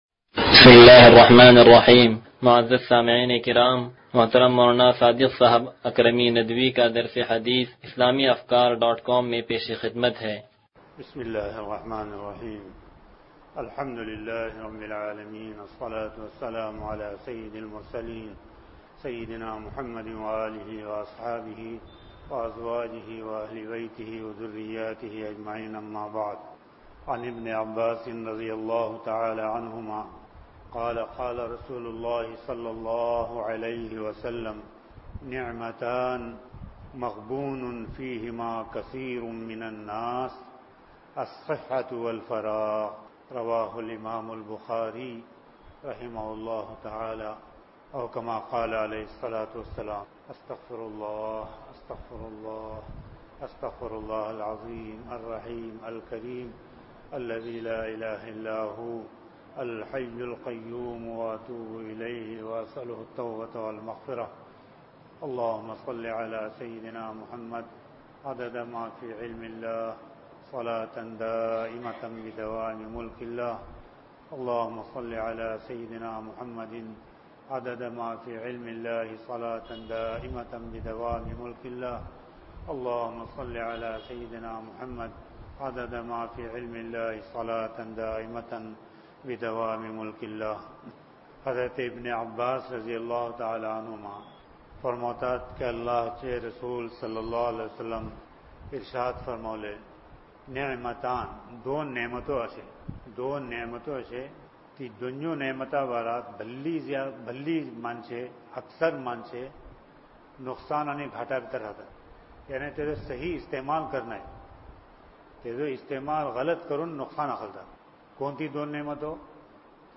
درس حدیث نمبر 0106